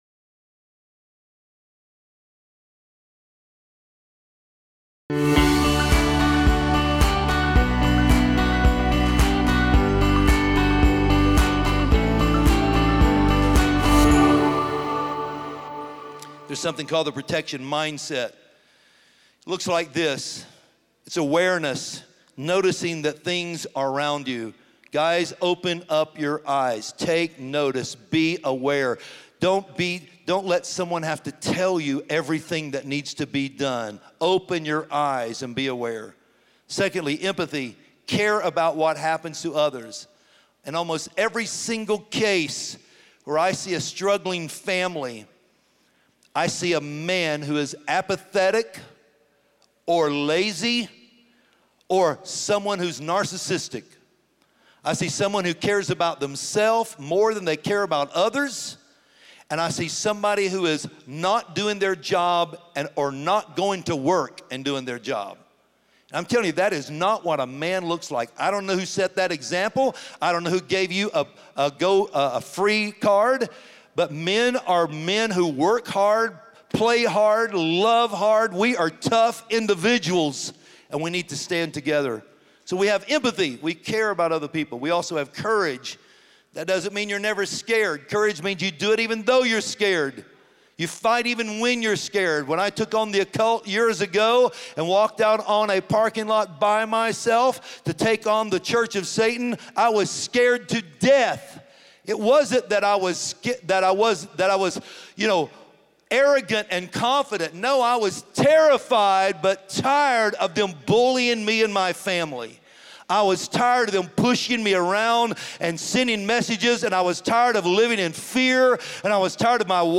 Join us this week for the sermon “Do Not Quit- Keep Going.”